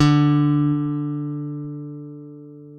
ALEM PICK D3.wav